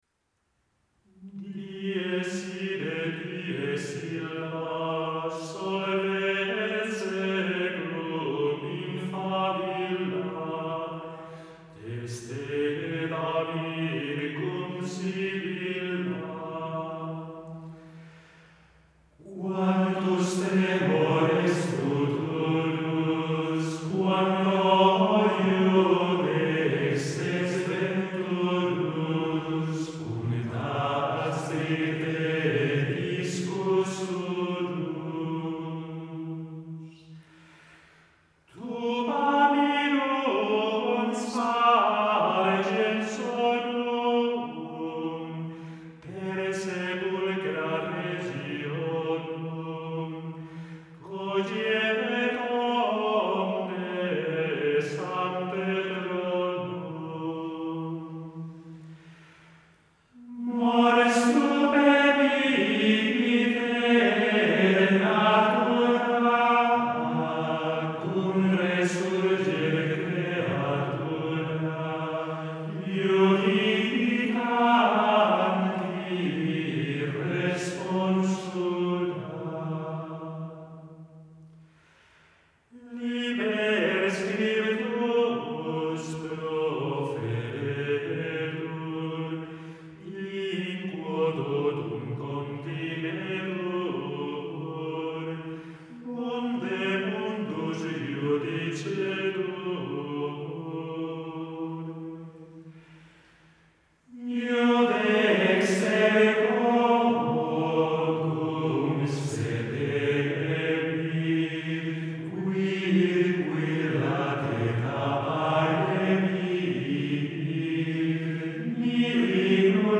Dies irae. Secuencia. Modo I. Interpretes: Schola Antiqua.